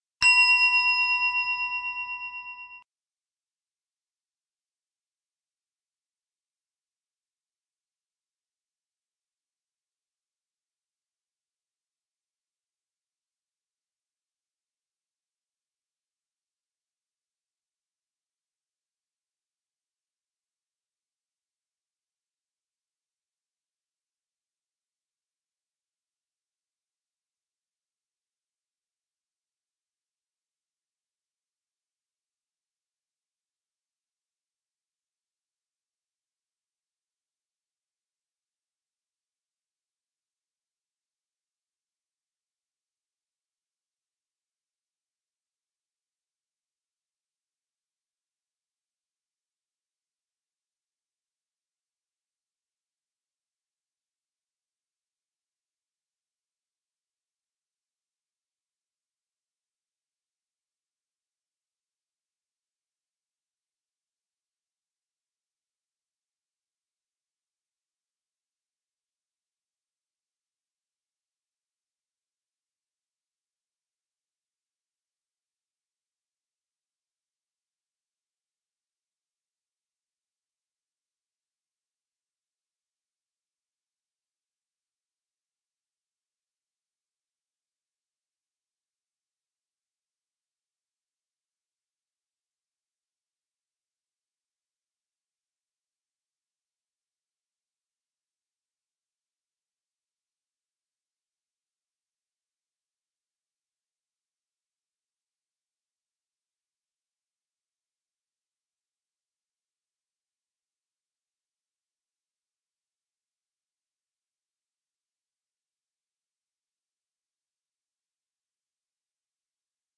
Dyad Gong – Click this header
ei-dyad-gong-with-bell-at-start-2020.mp3